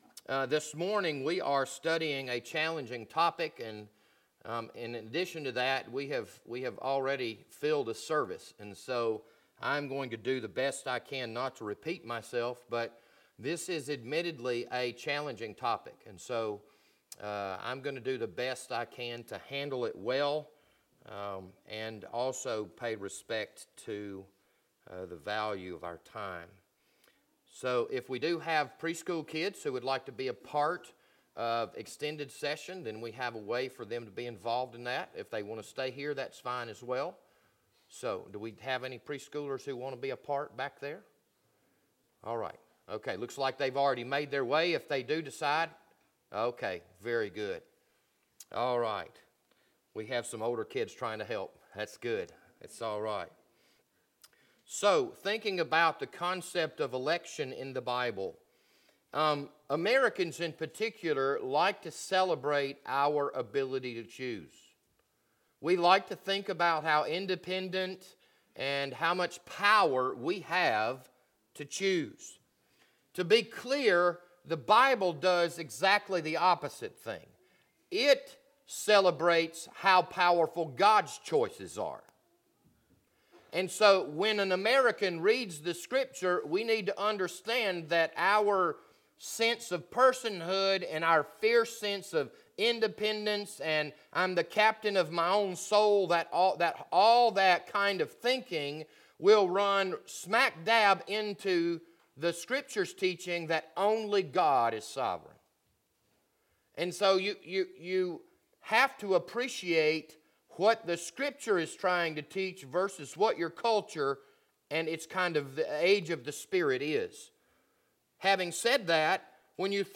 This Sunday morning sermon was recorded on December 8th, 2019.